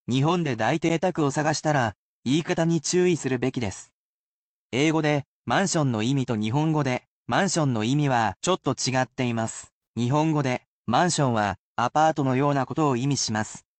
Though you should certainly repeat after the words to assist you in learning them,the sentences are at normal speed, so you probably would benefit most from using these as listening practice.